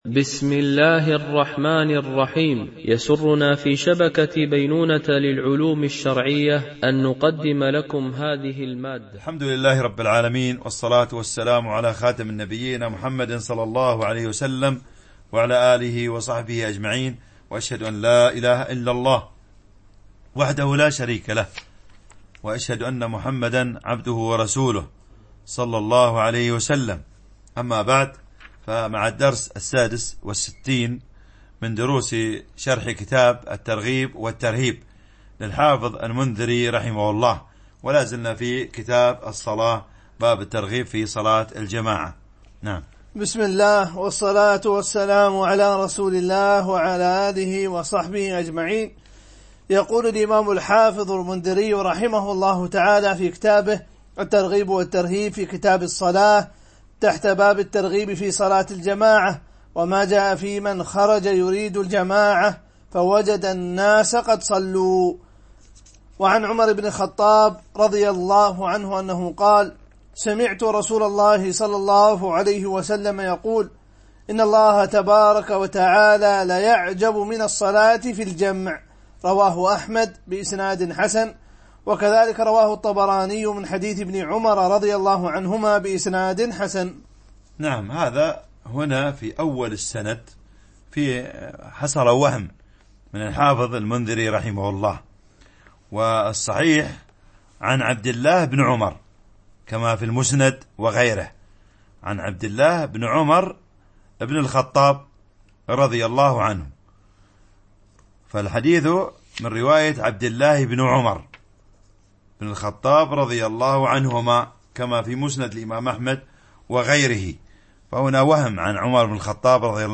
شرح كتاب الترغيب والترهيب - الدرس 65 ( كتاب الصلاة .الحديث 577 - 600)
MP3 Mono 22kHz 32Kbps (CBR)